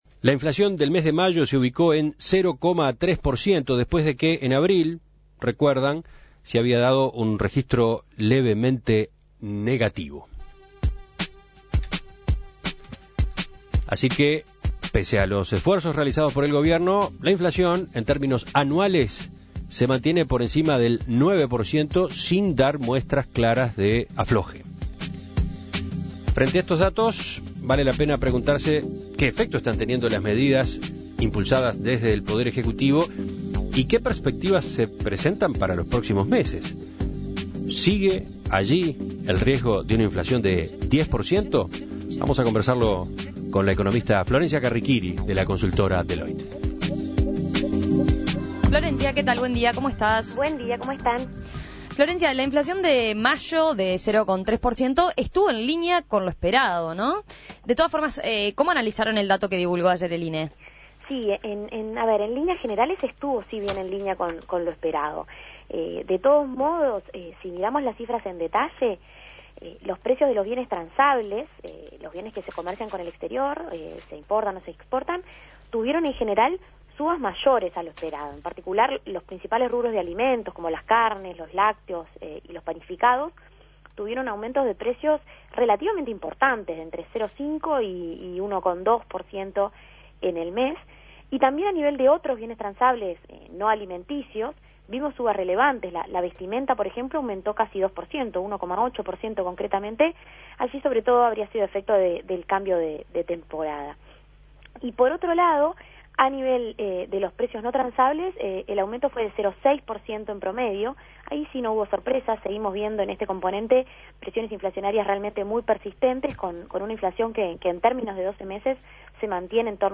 Análisis y perspectivas